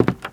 STEPS Wood, Creaky, Walk 11.wav